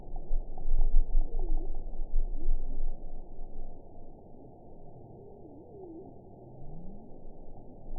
event 922295 date 12/29/24 time 04:17:42 GMT (11 months ago) score 9.08 location TSS-AB06 detected by nrw target species NRW annotations +NRW Spectrogram: Frequency (kHz) vs. Time (s) audio not available .wav